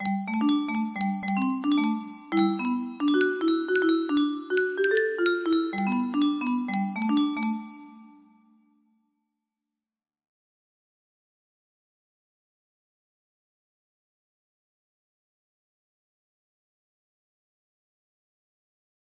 silly tune music box melody